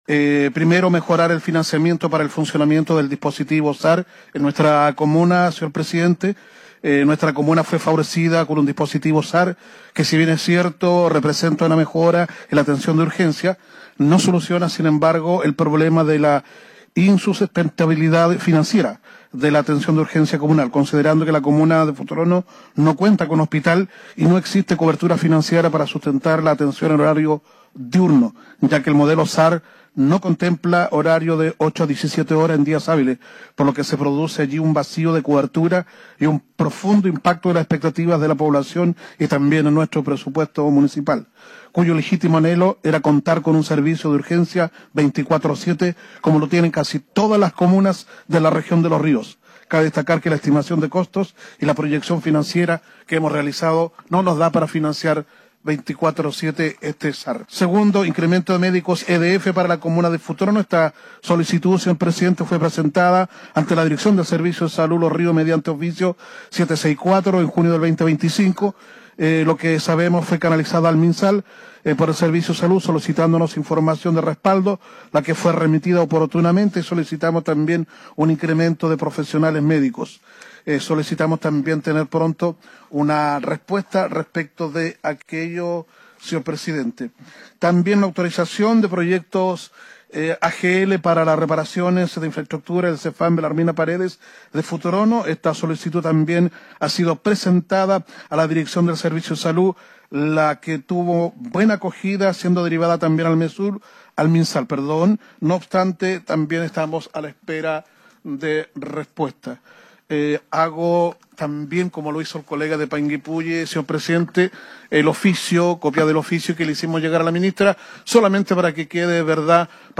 La reunión, desarrollada en la sala de conferencias Deloitte de la Facultad de Ciencias Económicas y Administrativas de la Universidad Austral de Chile, tuvo como objetivo abordar los desafíos y brechas que enfrentan las comunas de la Región de Los Ríos en materia sanitaria, especialmente en lo relacionado con la red de atención primaria y hospitalaria.
CUNA-ALCADE-FERNANDO-FLANDEZ-EXPONE.mp3